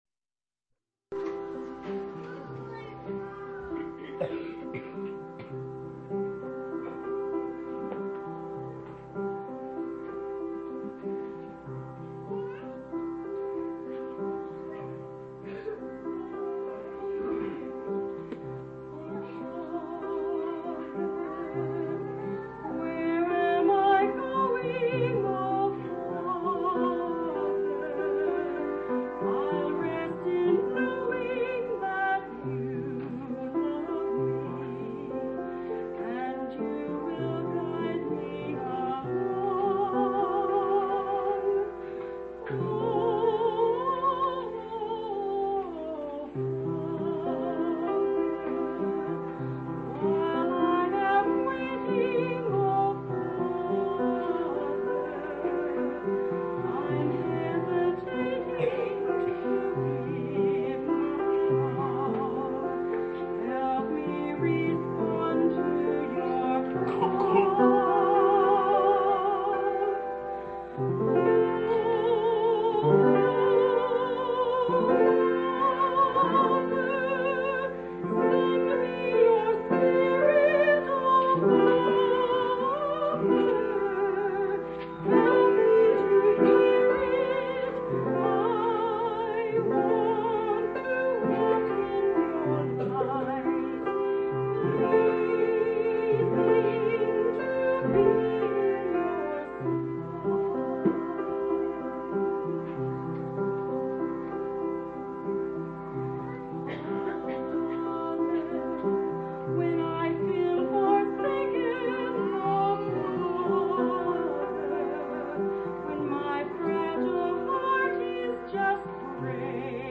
11/24/1984 Location: Phoenix Reunion Event: Phoenix Reunion